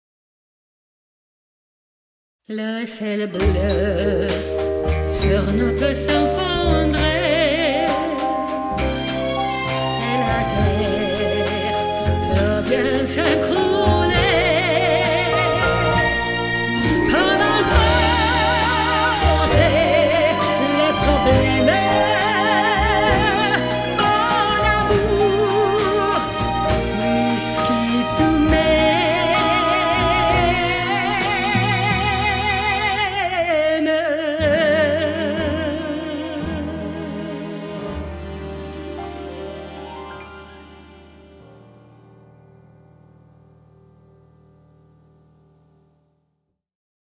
シャンソン